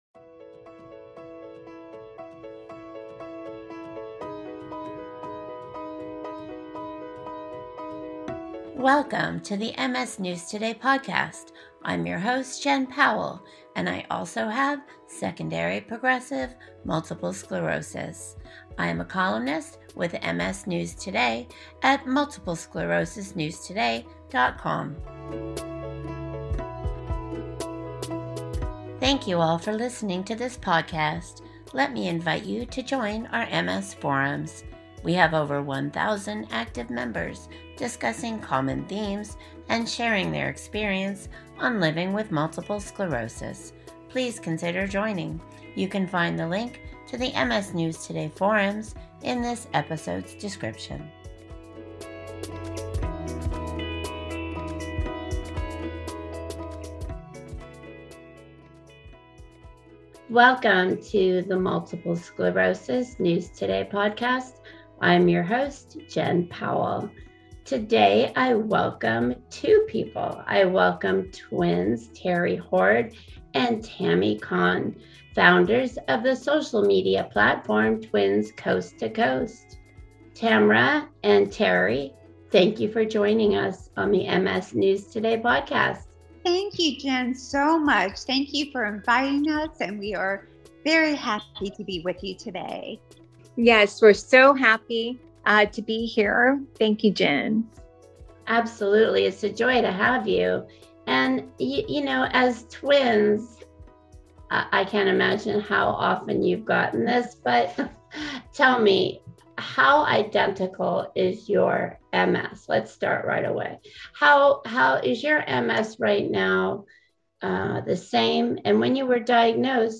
Listen in as these sisters-turned-advocates share how their life’s mission is to help others find their own way.